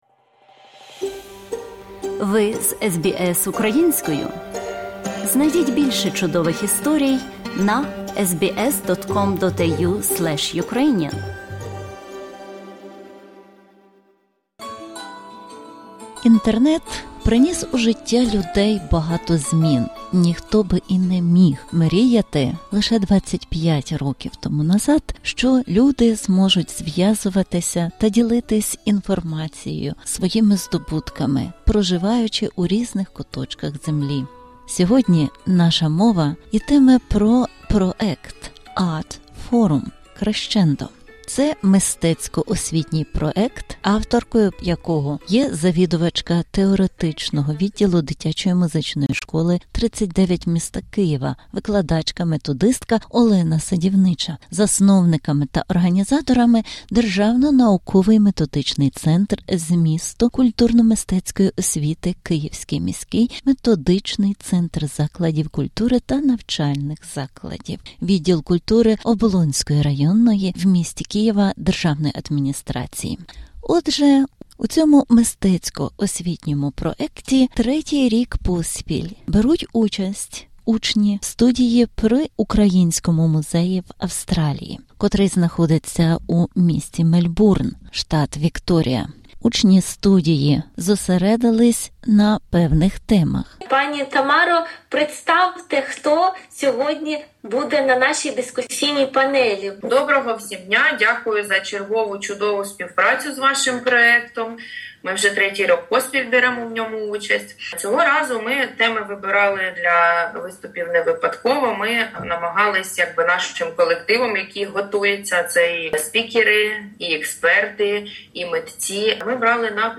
Репортаж із V Міжнародної студентської конференції ARS LONGA, що відбулася в рамках CRESCENDO ART FORUM – 2025, котра зібрала нові голоси у сфері мистецтва та культури з усього світу.